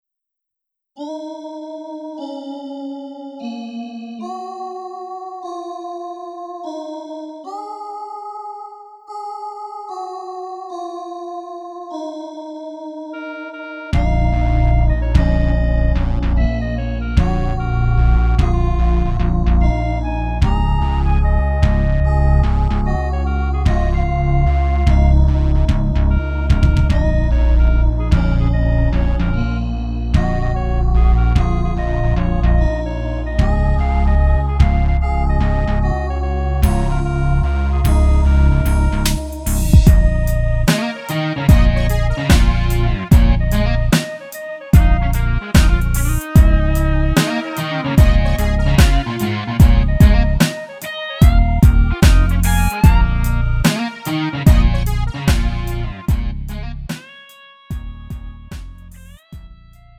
음정 -1키 2:18
장르 구분 Lite MR